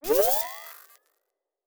pgs/Assets/Audio/Sci-Fi Sounds/Electric/Device 2 Start.wav at master